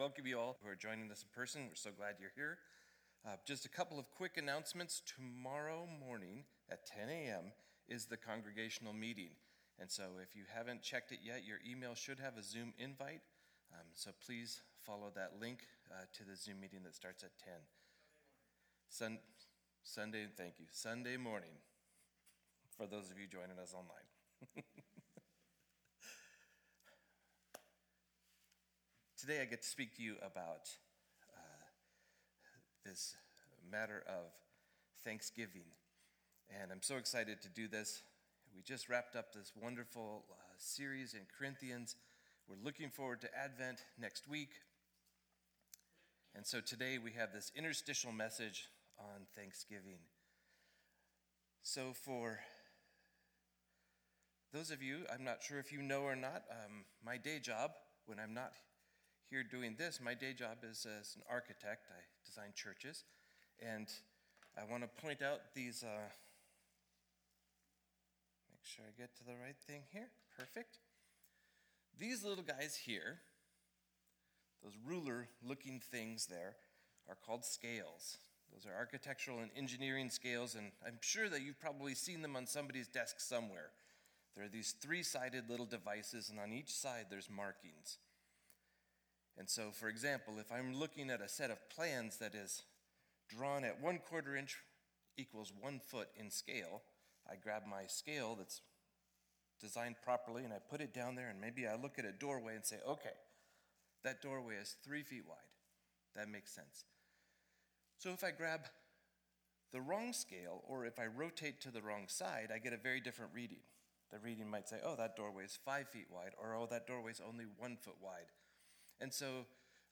2020-11-15 Sunday Service